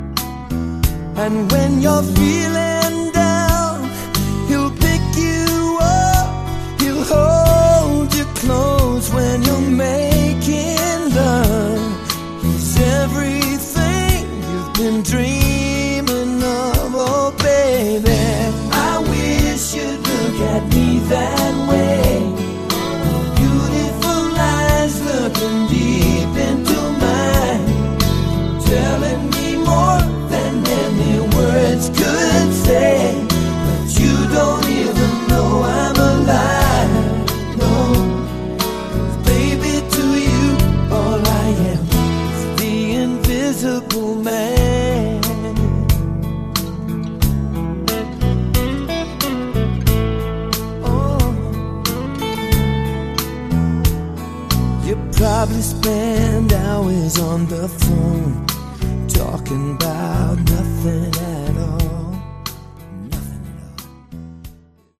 Category: Lite/West Coast AOR